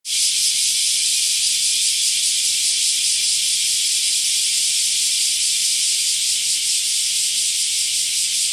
わしわしの声が鳴り響く季節になった。